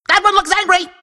Worms speechbanks
Grenade.wav